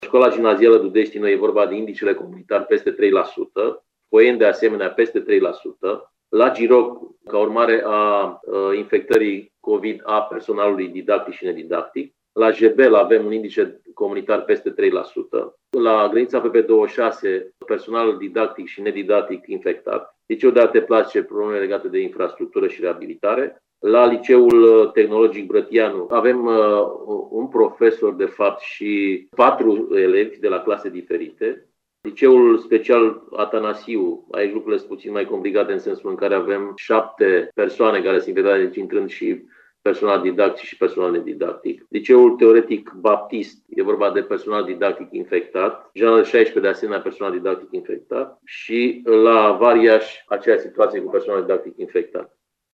În acest moment sunt 11 unităţi de învăţământ care aplică integral scenariul roşu. Inspectorul şcolar general, Marin Popescu, spune care sunt aceste școli şi cauza intrării în scenariul integral online.